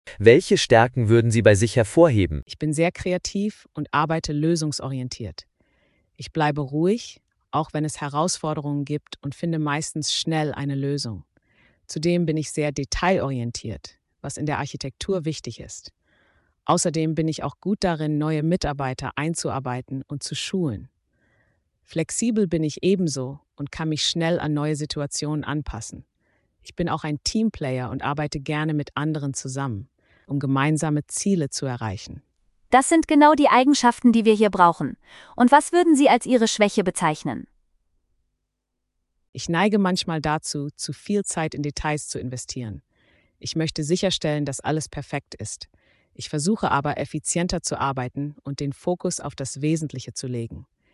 🎧 Typisches Vorstellungsgespräch für Architektinnen und Architekten – realistisch und praxisnah!
Komplettes Gespräch – Realistische Fragen und passende Antworten speziell für Architektinnen und Architekten